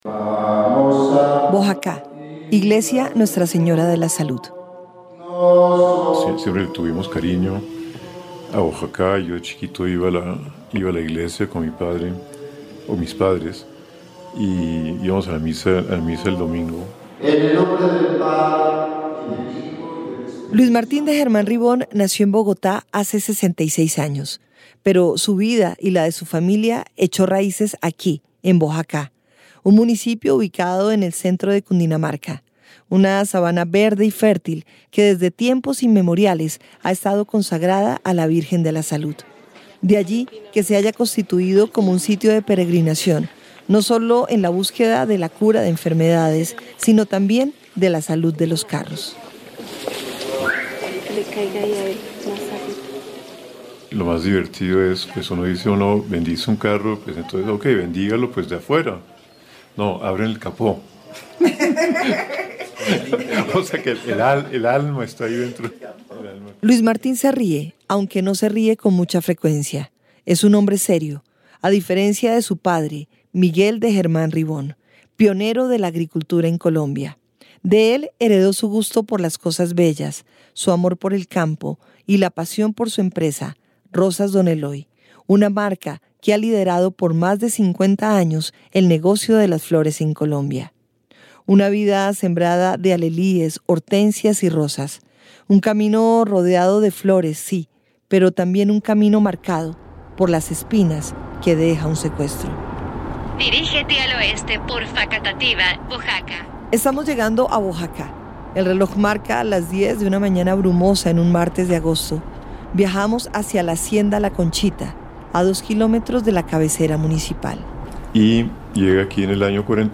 Serie radial basada en la publicación de 2019 del Centro Nacional de Memoria Histórica (CNMH): Voces que construyen : memorias de empresarios.